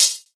Hat (48).wav